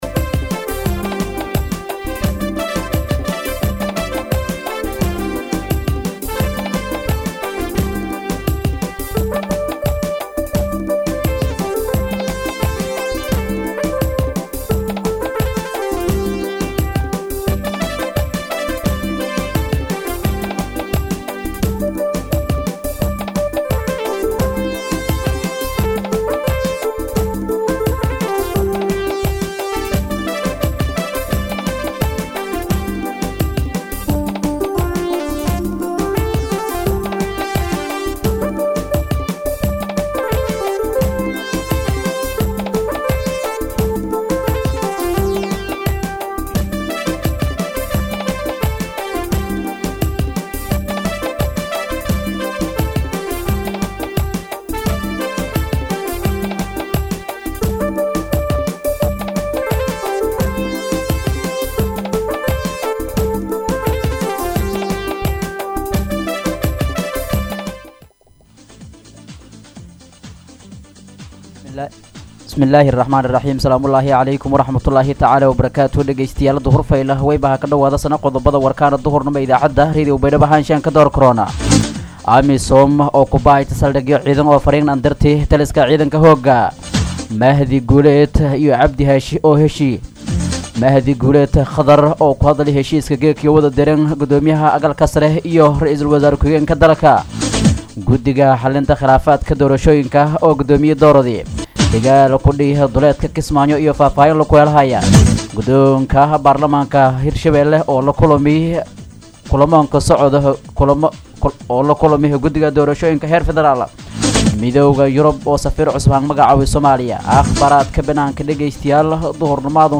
DHAGEYSO:-Warka Duhurnimo Radio Baidoa 5-9-2021